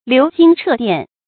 流星掣電 注音： ㄌㄧㄨˊ ㄒㄧㄥ ㄔㄜˋ ㄉㄧㄢˋ 讀音讀法： 意思解釋： 見「流星飛電」。